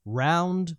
Voices / Male / Round.wav